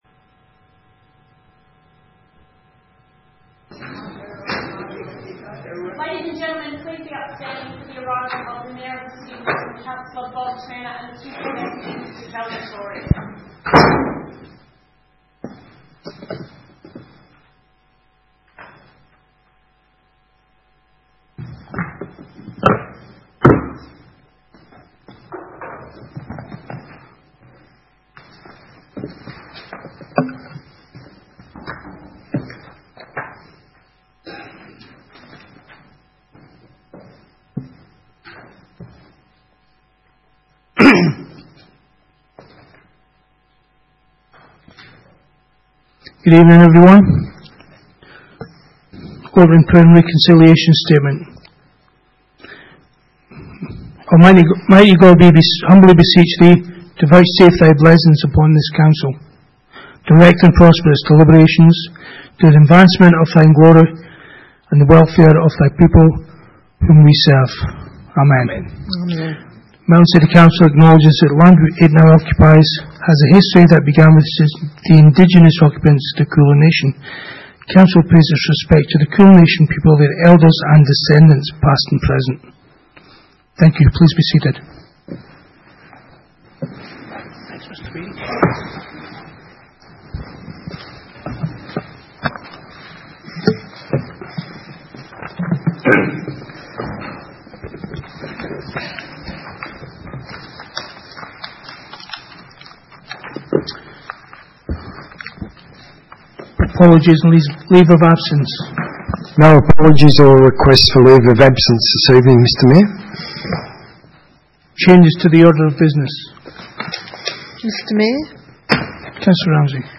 Ordinary meeting 5 March 2018